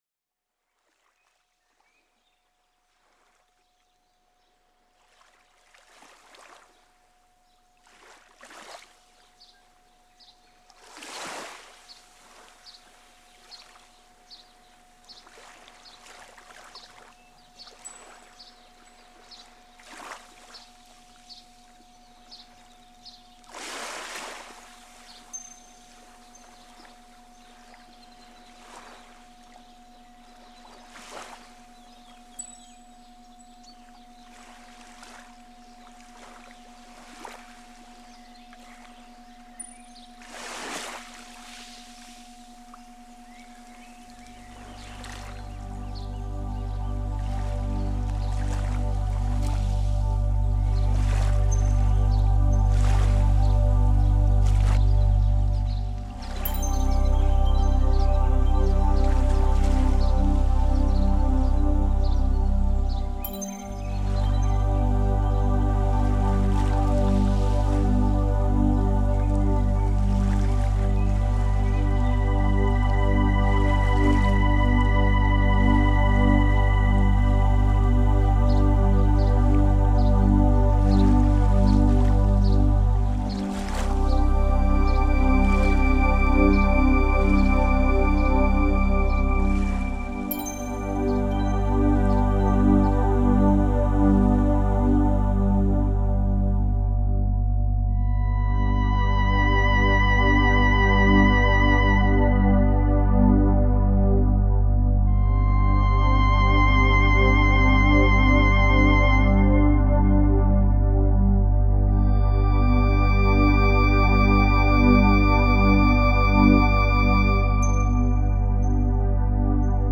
Ave Maria Miracle Chord 727 Hz.... Listen to 727 Hz Cure-All Universal Healing Rife Frequency, Bio/Vibroacoustic Music Therapy has many benefits.
Ave+Maria+727+Hz.mp3